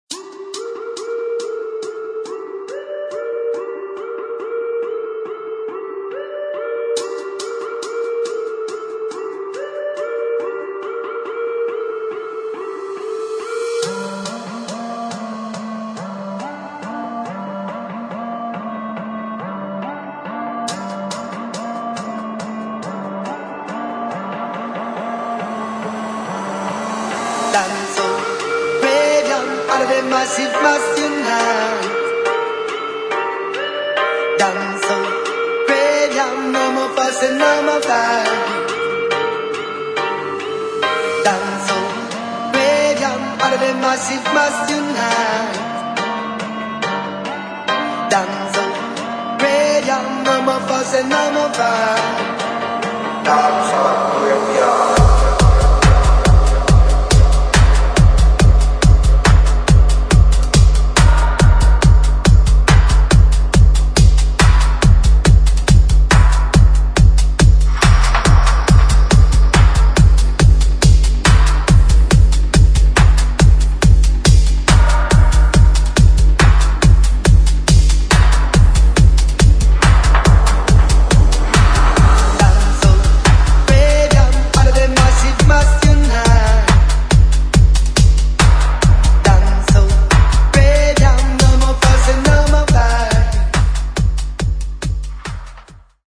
[ DUB / DUBSTEP ]